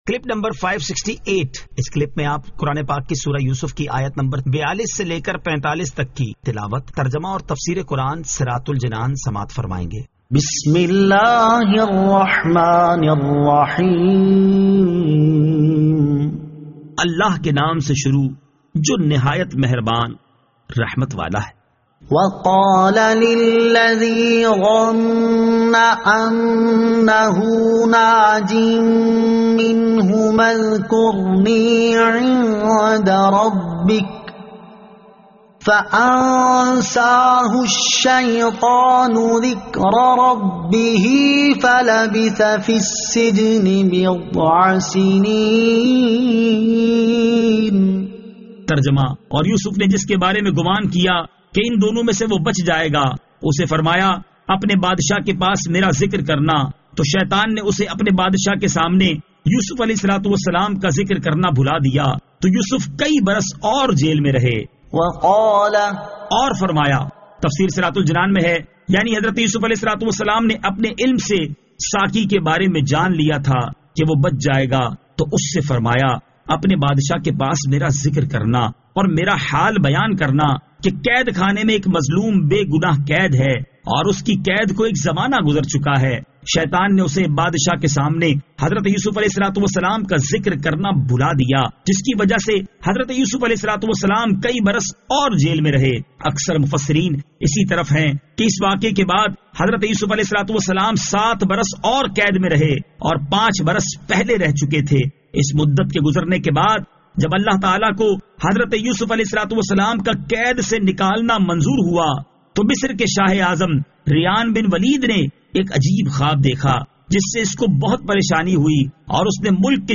Surah Yusuf Ayat 42 To 45 Tilawat , Tarjama , Tafseer